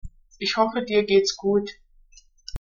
gut-abfallend.mp3